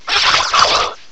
sovereignx/sound/direct_sound_samples/cries/clobbopus.aif at master